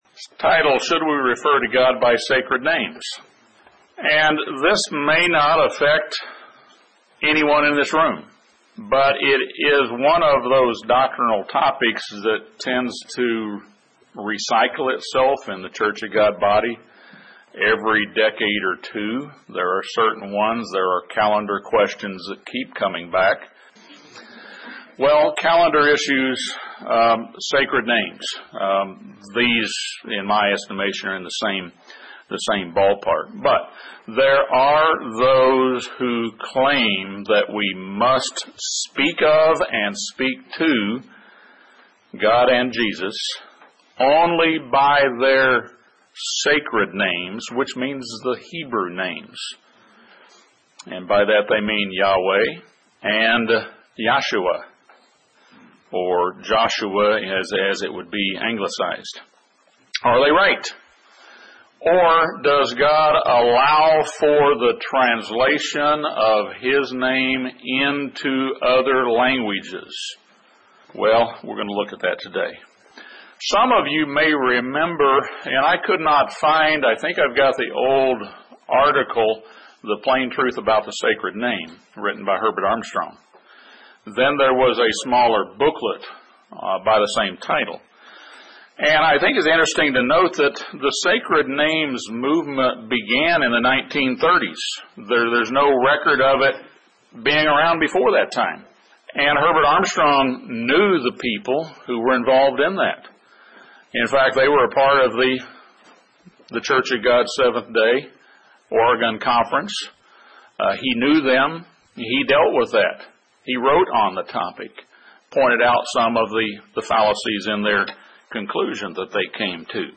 This sermon discusses many scriptural precedents of translating God’s names from one language into another.